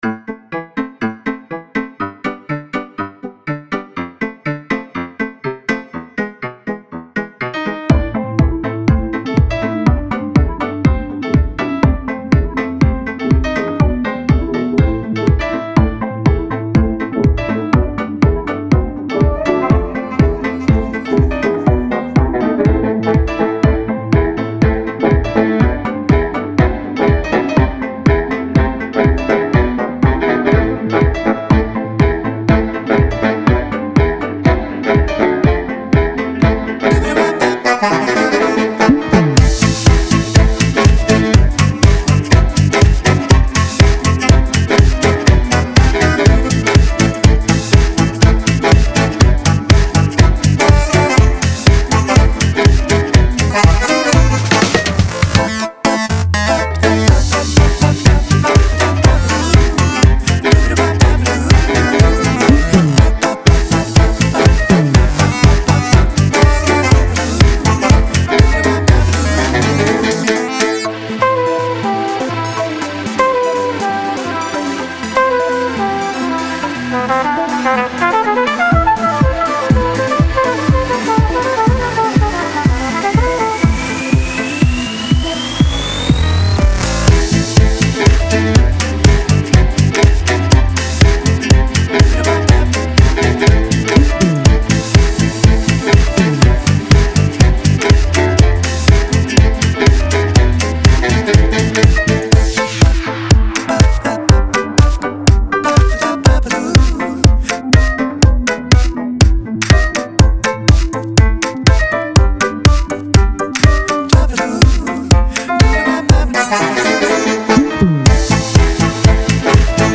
電子搖擺 有趣 俏皮 | 古怪 | 快樂
122 BPM